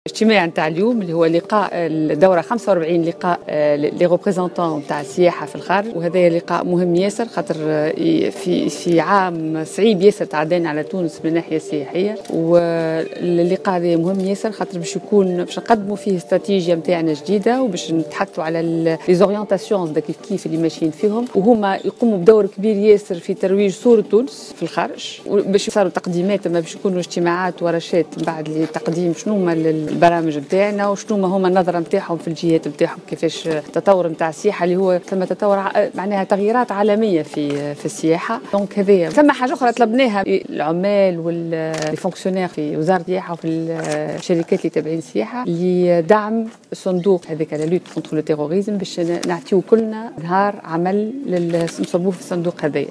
وقالت وزيرة السياحة والصناعات التقليدية على هامش الملتقى السنوي لممثلي الديوان التونسي للسياحة بالخارج الذي أشرفت عليه اليوم في الحمامات، إن الموسم السياحي كان صعبا هذا العام واضافت في تصريحات صحفية، أن مثل هذا الملتقى سيساهم في وضع استراتيجية جديدة للقطاع وتحديد توجهاته.